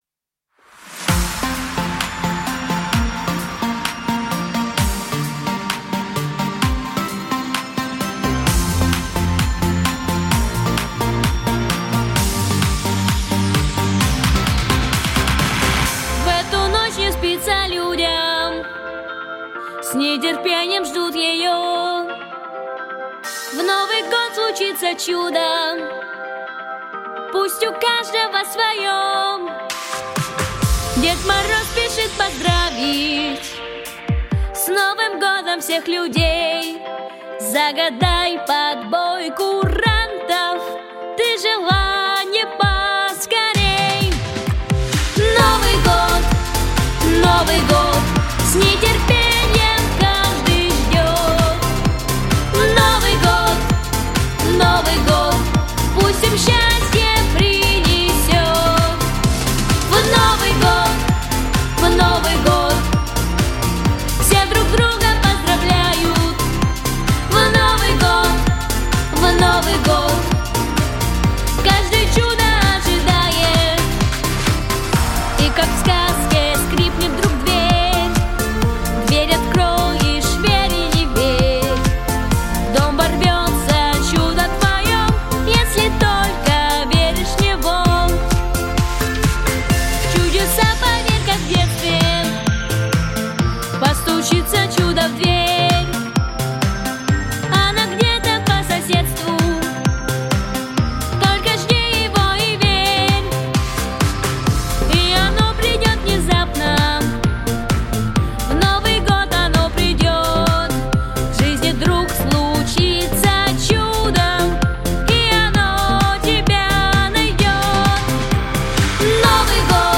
🎶 Детские песни / Песни на Новый год 🎄